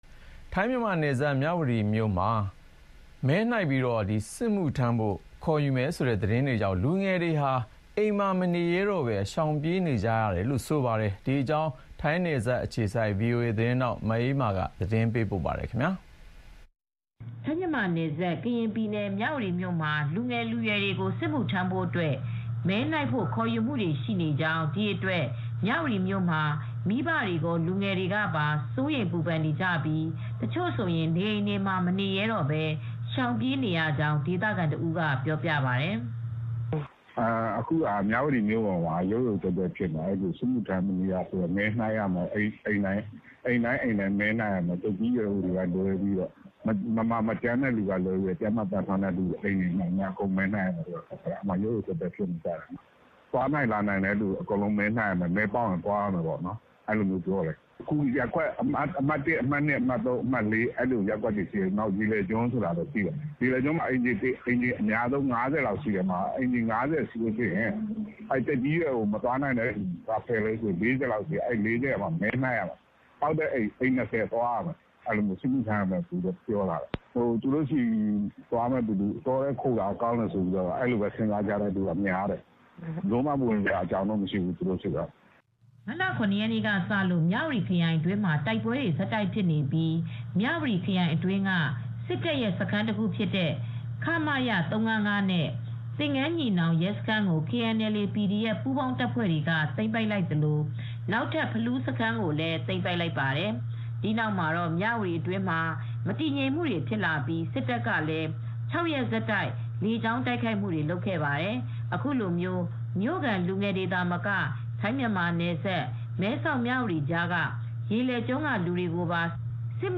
ထိုင်း-မြန်မာနယ်စပ် ကရင်ပြည်နယ် မြဝတီမြို့မှာ လူငယ်လူရွယ်တွေကို စစ်မှုထမ်းဖို့ မဲနှိုက်ဖို့ ခေါ်ယူနေတာကြောင့် မြဝတီမြို့မှာ မိဘတွေကော လူငယ်တွေပါ စိုးရိမ်ပူပန်ပြီး တချို့နေအိမ်တွေကနေ ရှောင်ပြေးနေရကြောင်း ဒေသခံတဦးကအခုလိုပြောပြပါတယ်။